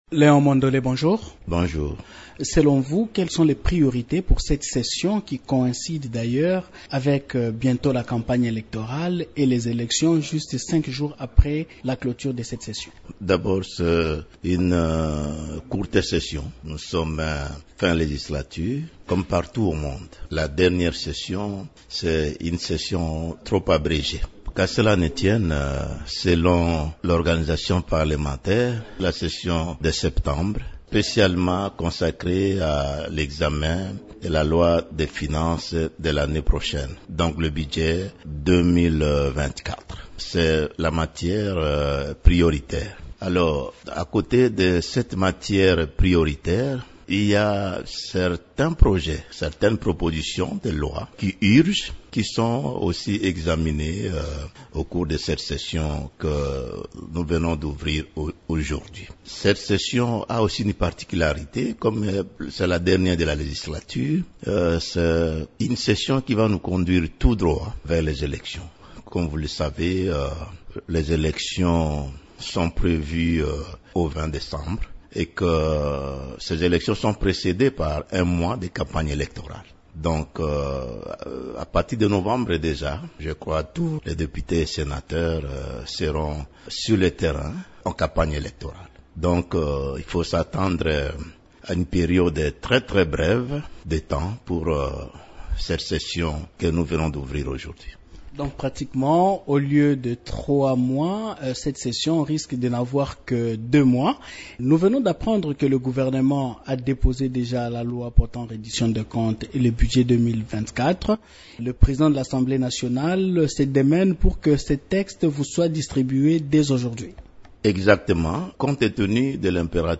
Invité de Radio Okapi ce lundi 18 septembre, le député Mondole explique qu’en plus de l’examen du budget 2024, le Parlement va examiner des lois de réforme urgentes.